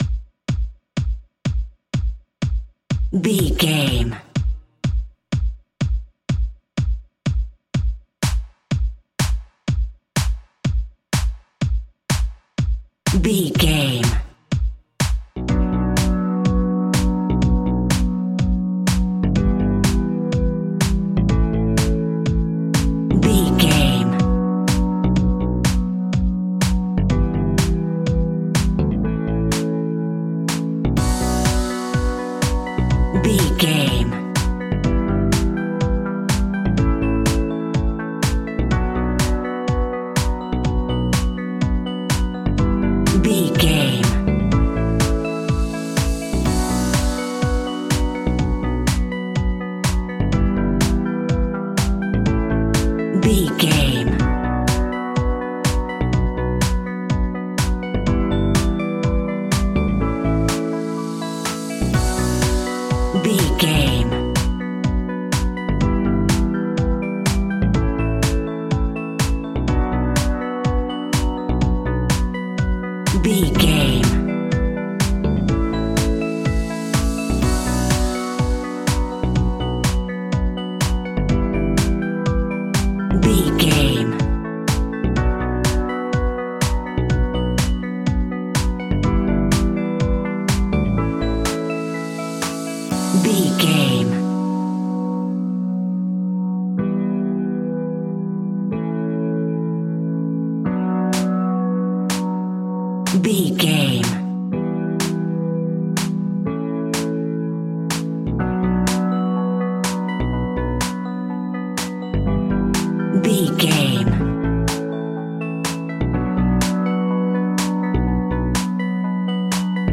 Aeolian/Minor
groovy
hypnotic
synthesiser
drum machine
electric guitar
bass guitar
funky house
nu disco
upbeat
funky guitar
clavinet